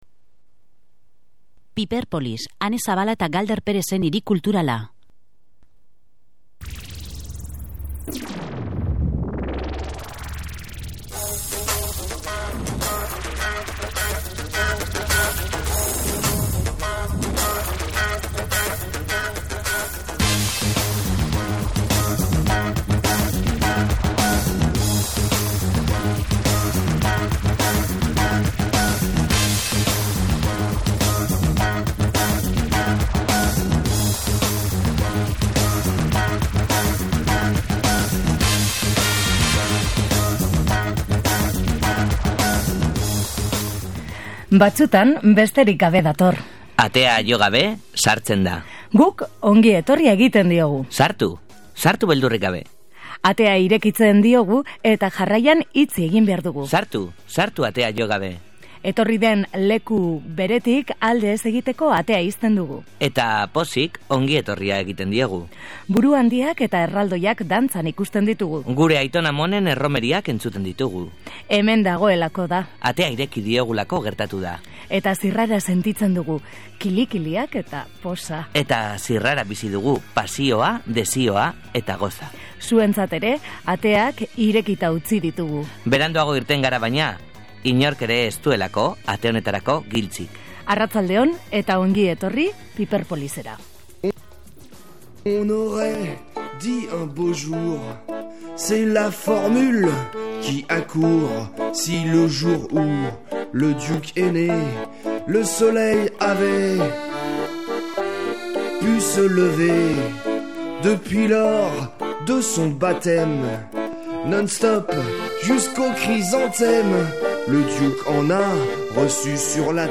Irrati nobela bueltan etorri da bere 101. atala emititzeko. “Non hago?” deitutako atalean, Trollope hodeitxoarekin bateraz, zeruan, ke artean galduta dago lurrera bueltatzeko plana antolatzen.